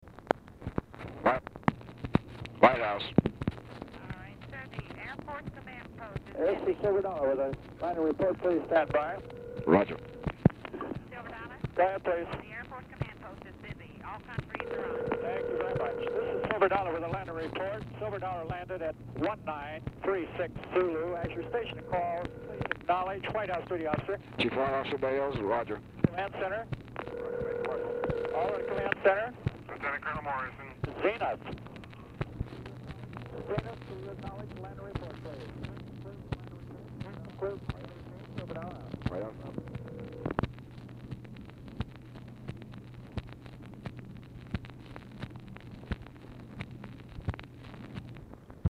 Telephone conversation
UNDATED AIRCRAFT COMMUNICATION RECORDED ON BELT
Format Dictation belt
Other Speaker(s) UNIDENTIFIED FEMALE